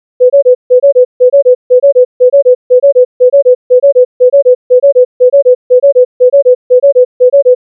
• sequence of sounds with a repeating pattern LHL-LHL-... (where L=low tone and H=high tone)
• when the frequency separation of the tones is small, the sequence is perceived as a single auditory stream [
sound-horse.wav